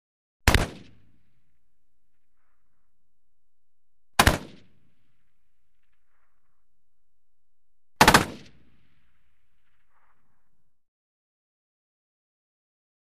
Tank Machine Gun: Bursts; Exterior Tank Machine Gun Bursts. Three Short Bursts With Long Echoey Roll Off. Medium Close Perspective.